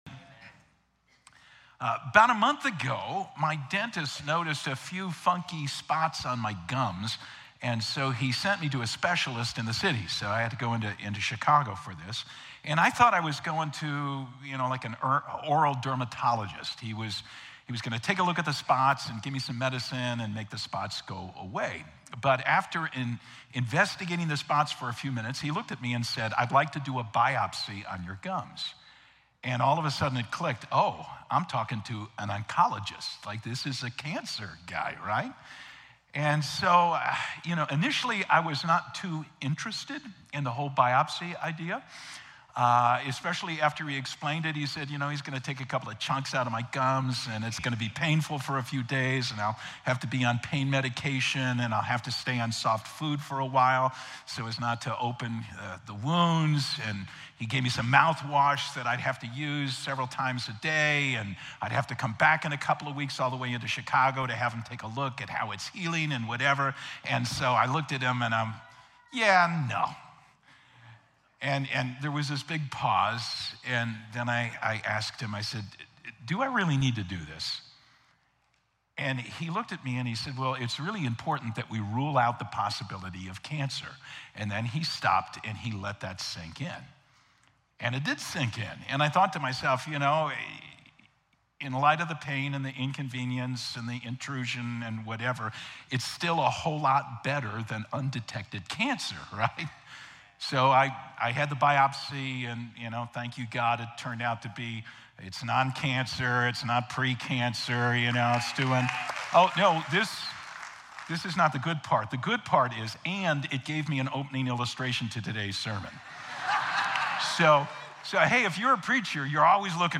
3-30-25-Sermon.mp3